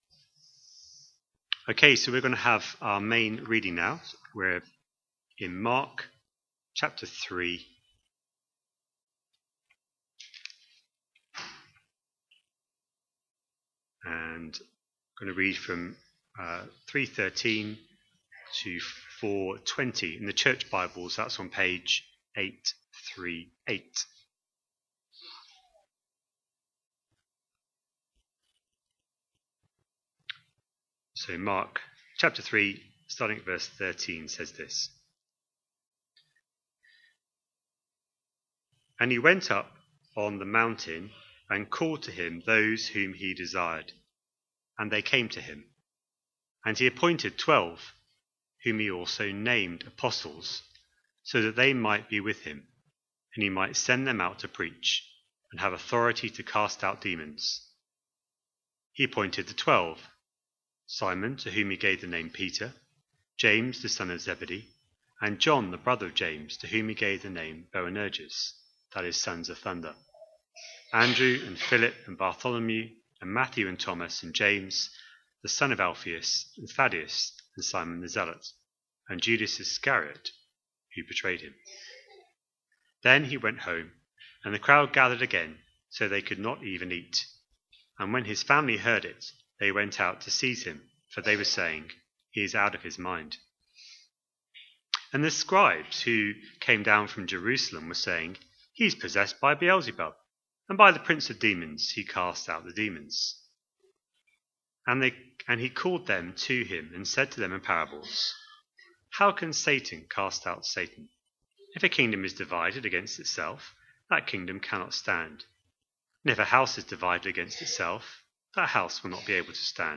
A sermon preached on 2nd November, 2025, as part of our Mark 25/26 series.